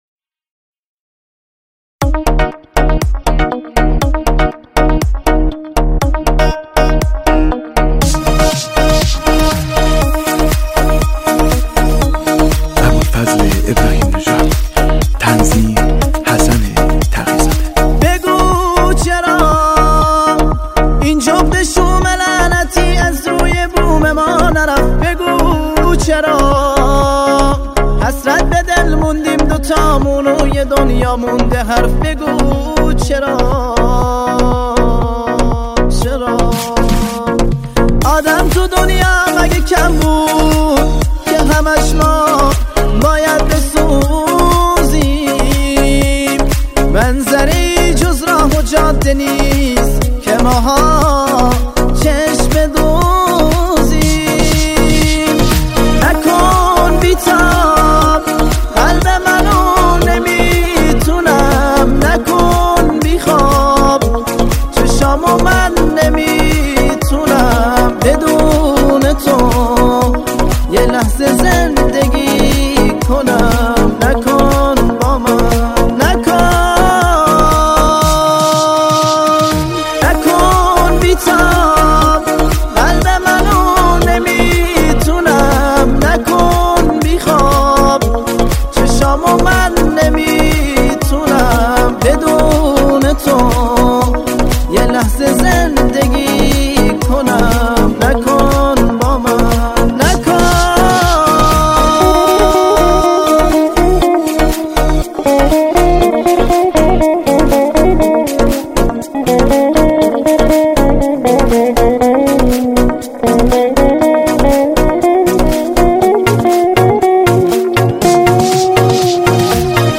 دسته بندی : دانلود آهنگ غمگین تاریخ : سه‌شنبه 7 ژانویه 2020